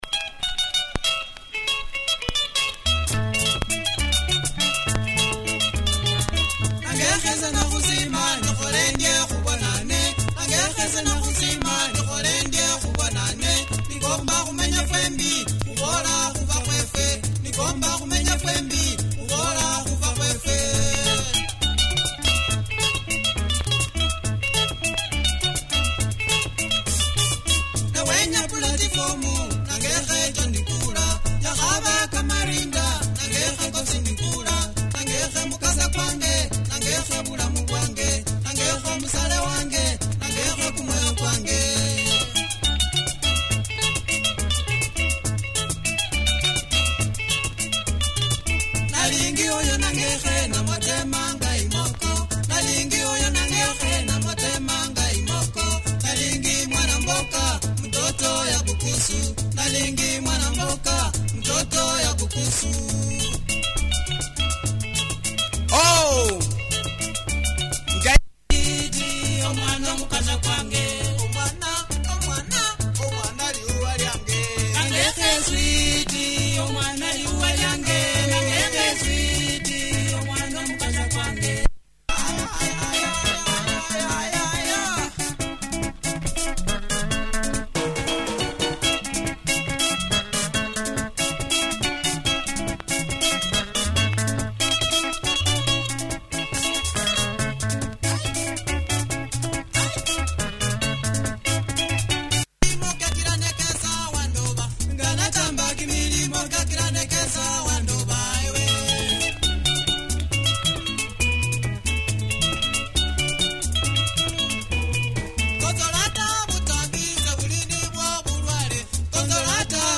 Tight Luhya benga, check audio of both sides! https